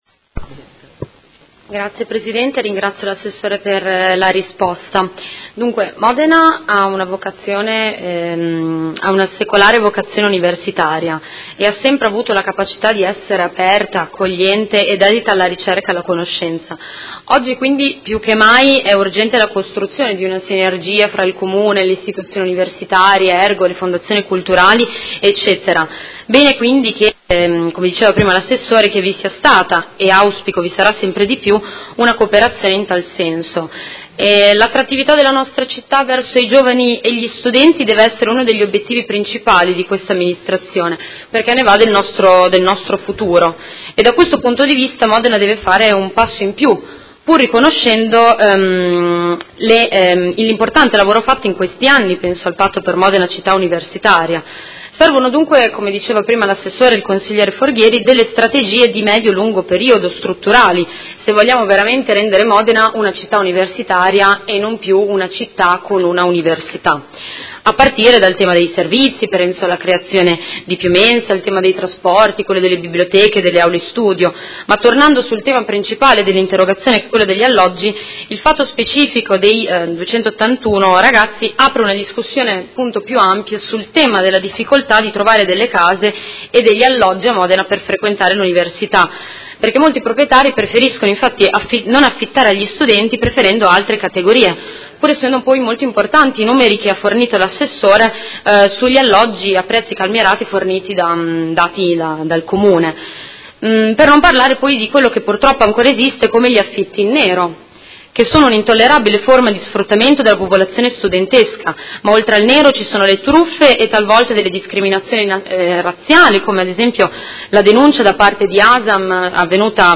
Seduta del 23/11/2017 Replica a risposta Assessore Cavazza. Interrogazione della Consigliera Venturelli (PD) avente per oggetto: Soluzioni abitative per risolvere il disagio degli studenti fuori sede.